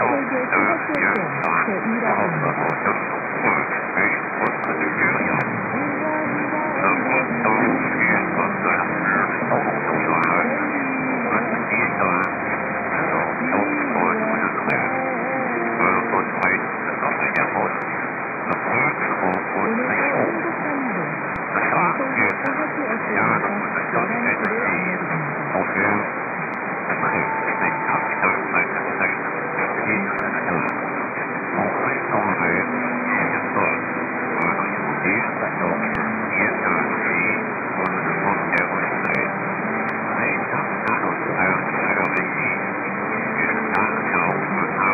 > The KBS station on 603 had a better than average signal as well.
> the co-channel CRI transmitter just after the time tone.